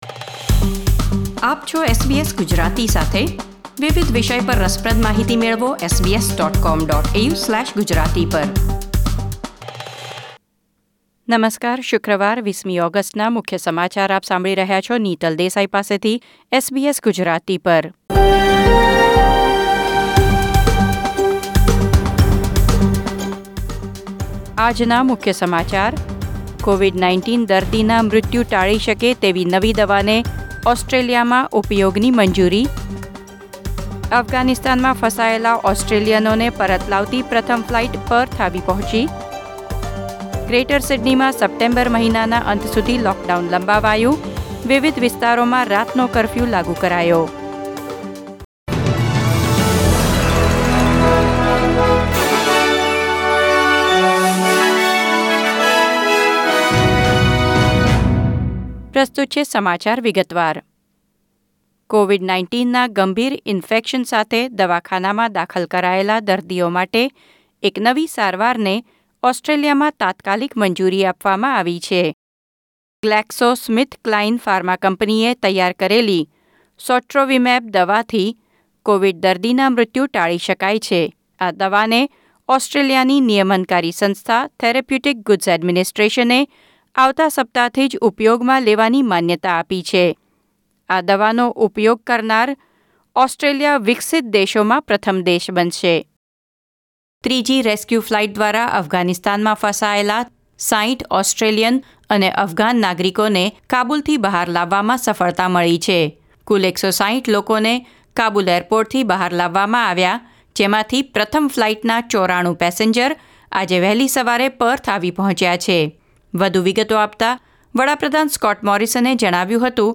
SBS Gujarati News Bulletin 20 August 2021